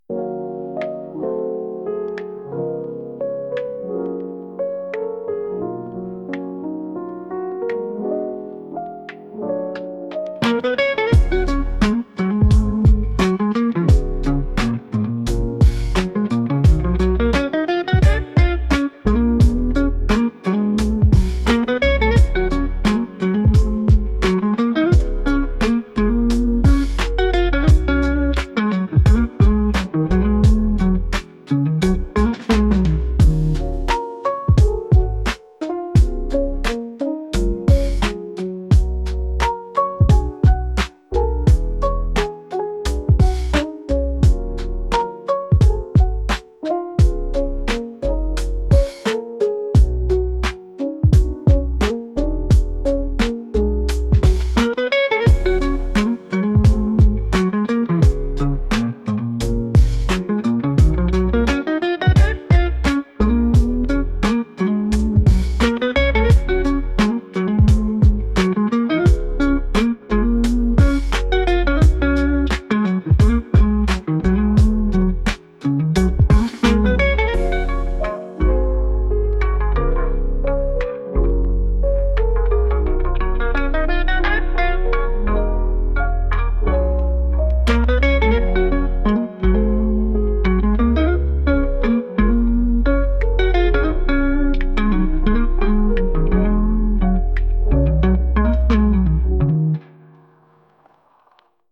宿題に長く取り組んでいる時にきくような曲です。